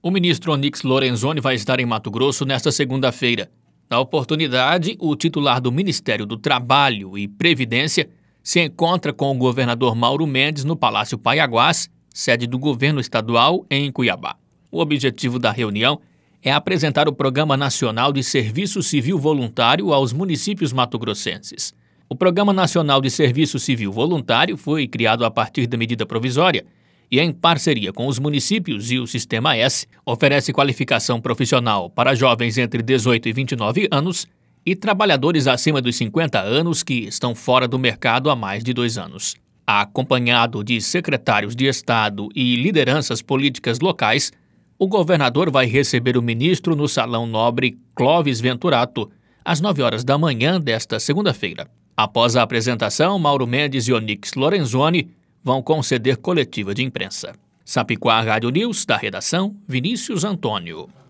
Boletins de MT 07 mar, 2022